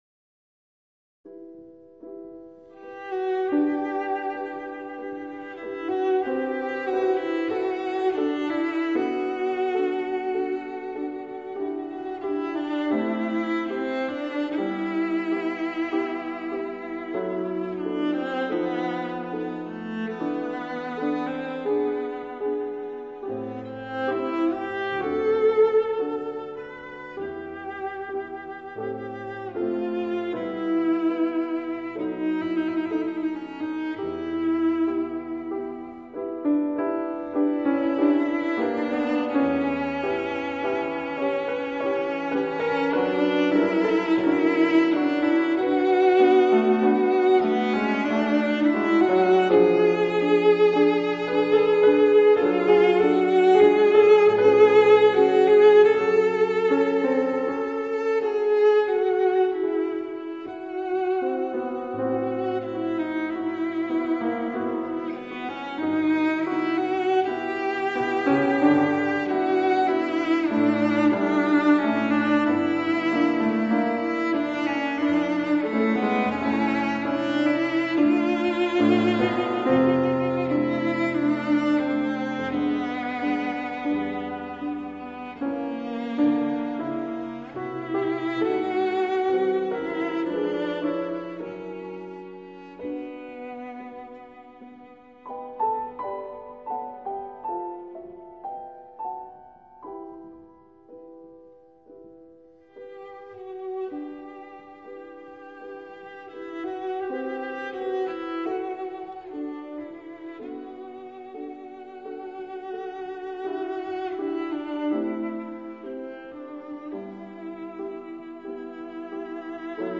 大提琴（Cello）